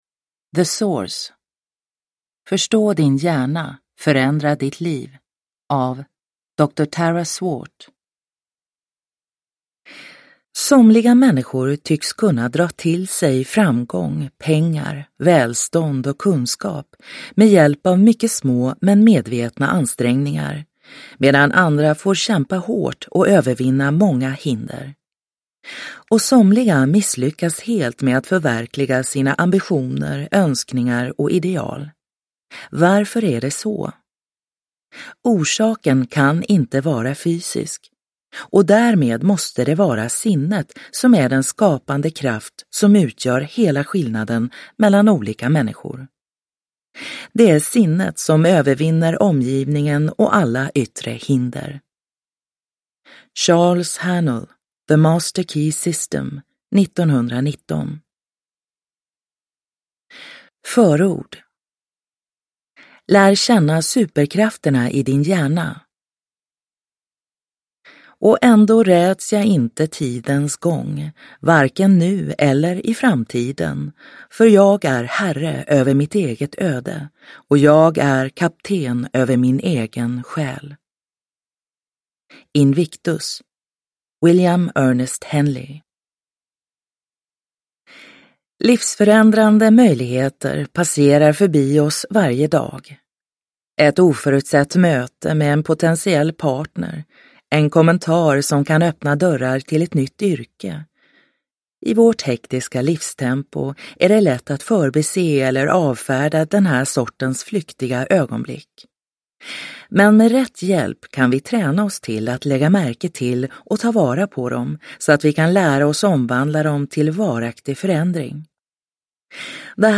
The Source : förstå din hjärna, förändra ditt liv – Ljudbok – Laddas ner